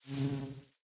Minecraft Version Minecraft Version 25w18a Latest Release | Latest Snapshot 25w18a / assets / minecraft / sounds / mob / bee / pollinate2.ogg Compare With Compare With Latest Release | Latest Snapshot
pollinate2.ogg